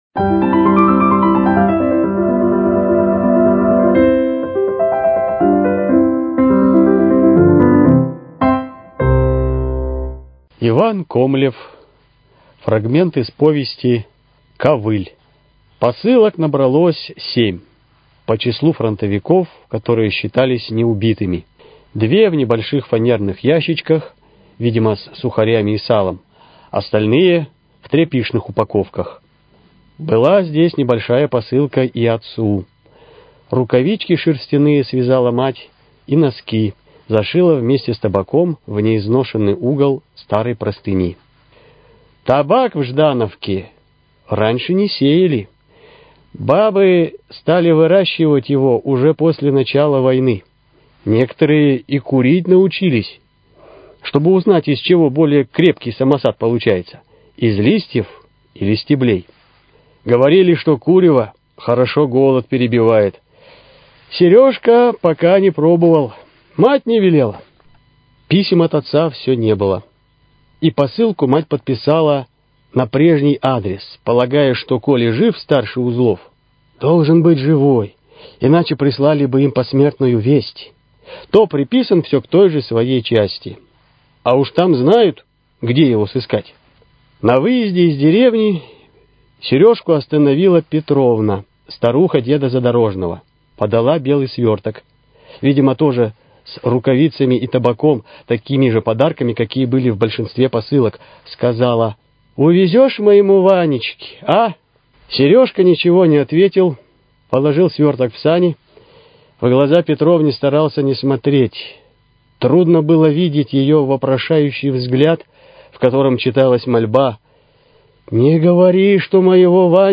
Литературные чтения: Фрагменты из произведений писателя Ивана Комлева